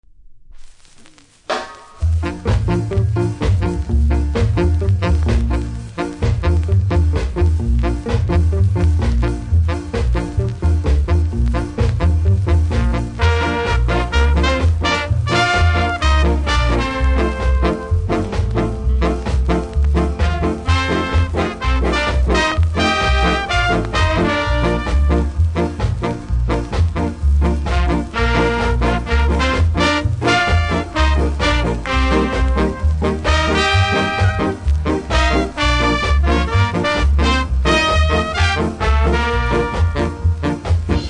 両面多少ノイズありますがキズは少なめです。